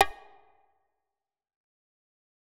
Destroy - Perc Congo.wav